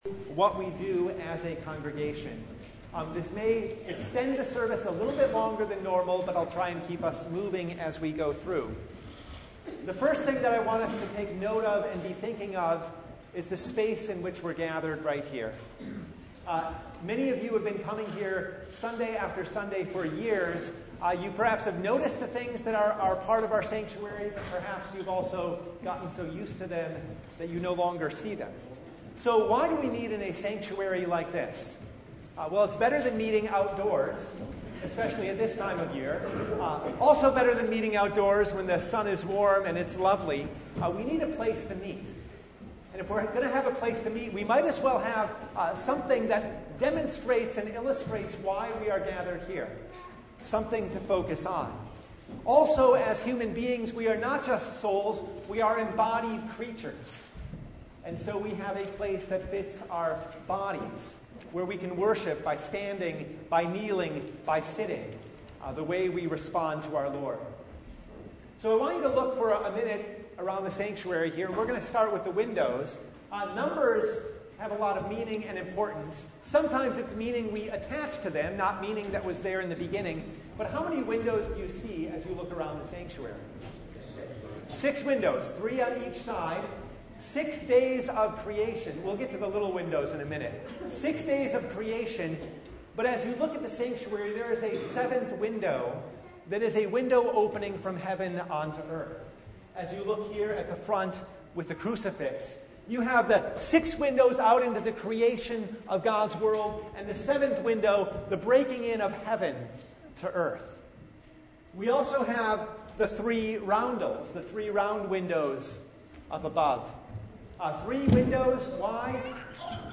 In addition to the service Pastor provides a narrative describing the sancurary and various parts of the service.